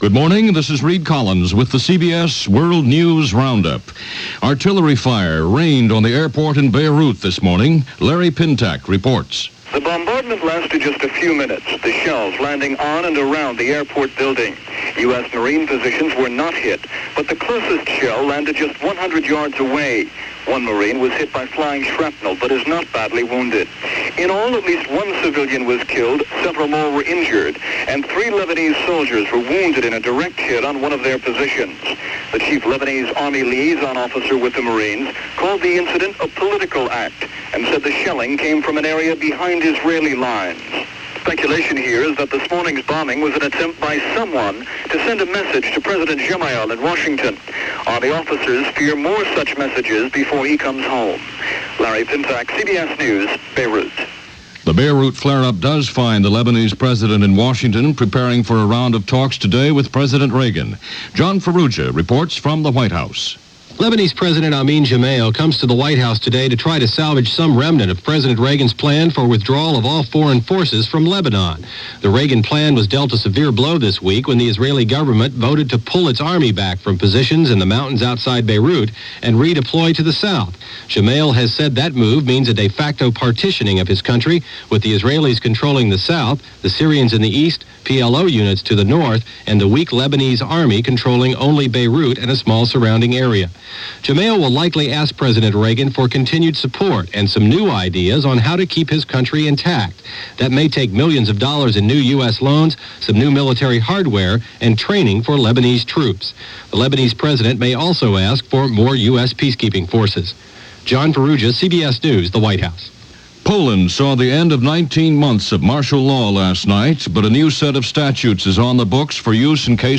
July 22, 1983 - The Odyssey Of Beirut - Martial Law Ends On Poland - A Heat Wave Grips The Eastern U.S. - News for this day in 1983.